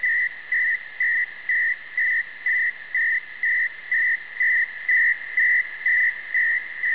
MERLIN/ALIS/RS-ARQ Rohde & Schwarz simplex ARQ, so far found in use by German, Italian, Nairobi and Turkish Diplo services, typically 228.7bd but reports of 457.0 have been noted. Usually found with an ACF=59.